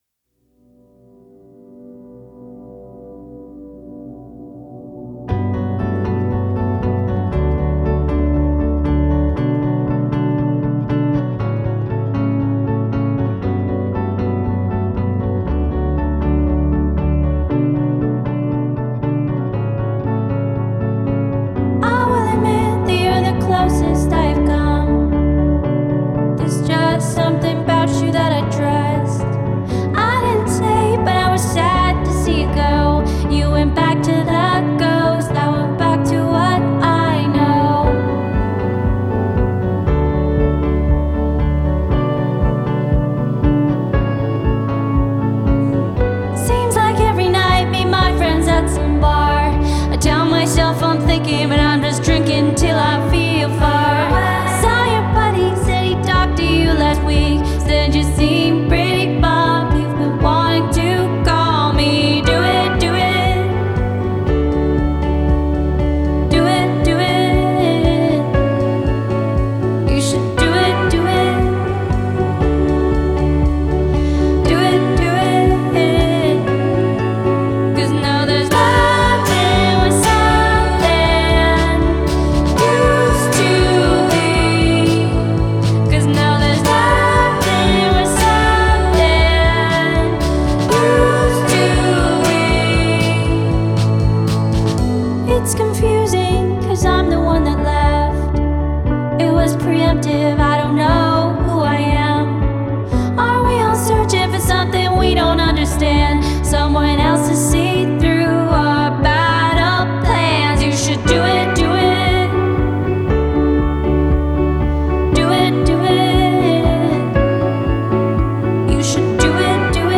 Genre: Indie Pop, Pop Folk, Singer-Songwriter